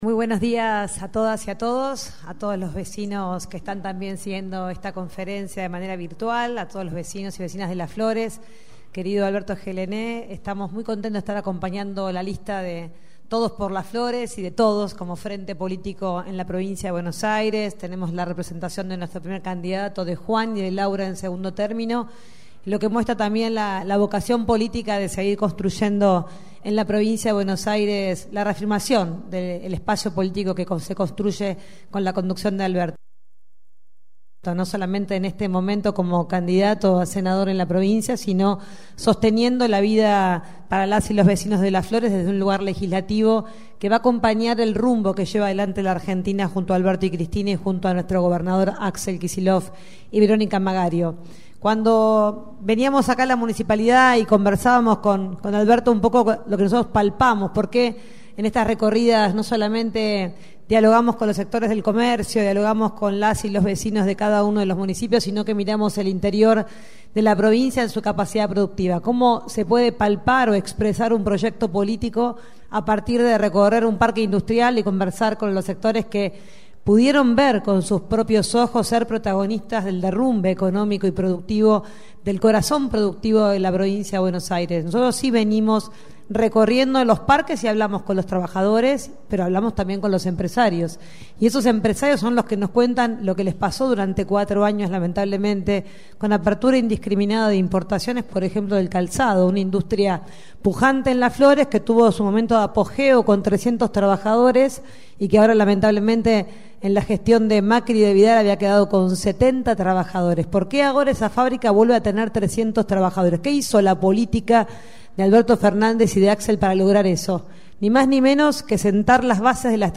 En conferencia de prensa que se llevó a cabo en el local partidario de Todos X Las Flores, Tolosa Paz afirmó que «estoy muy contenta de estar hoy acompañando a los precandidatos de esta ciudad.